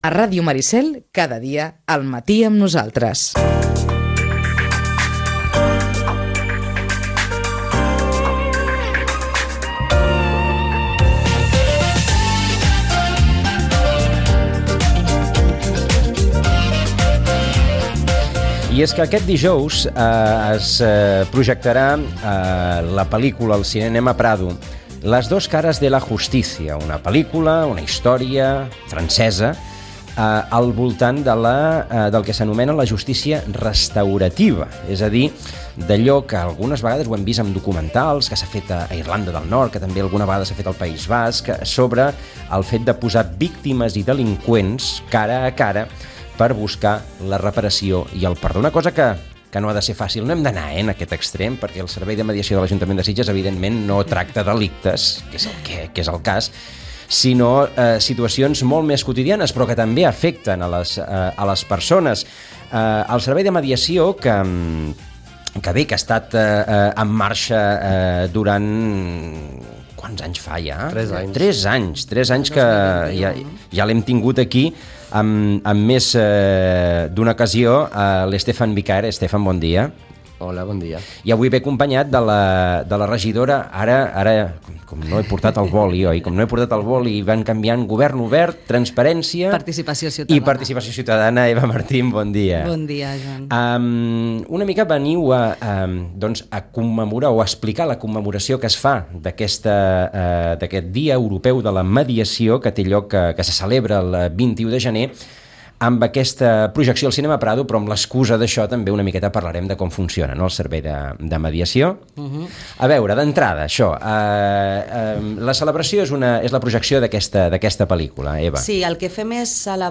Ens ho han explicat la regidora de Participació Ciutadana, Eva Martín